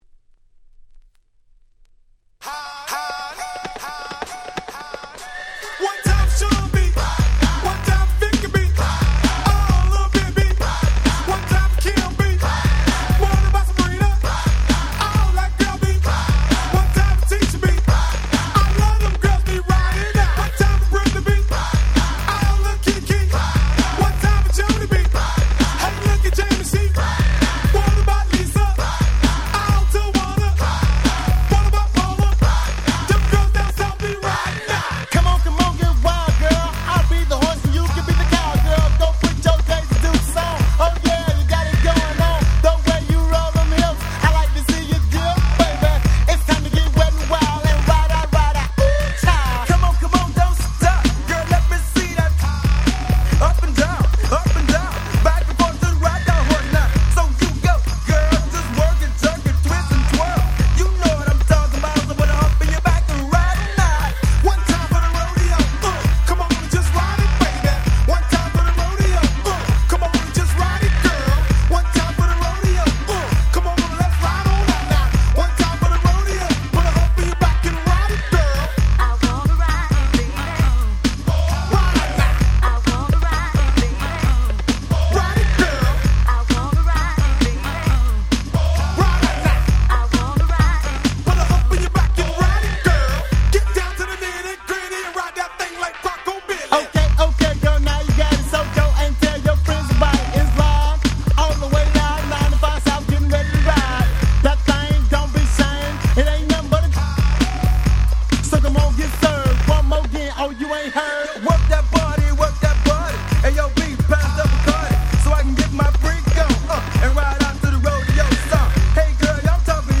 95' Big Hit Miami Bass / Hip Hop !!